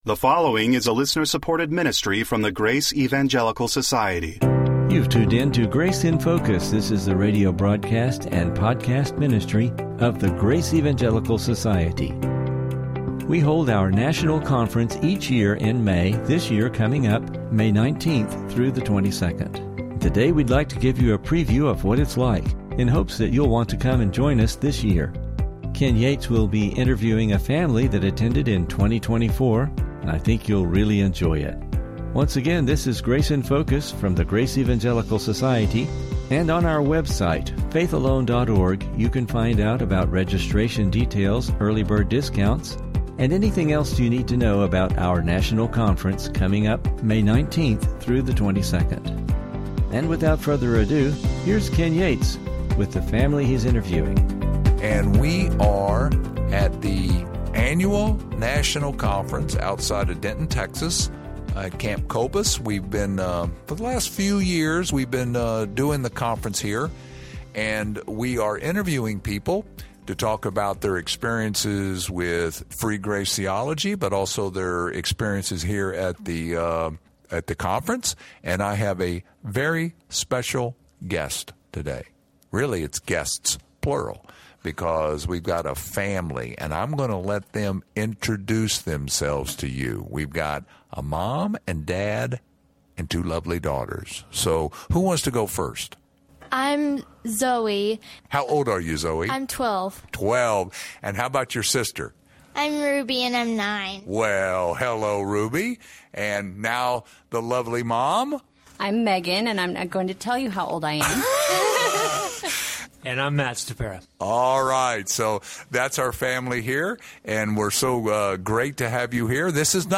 Interview – GES National Conference 2024 Attendees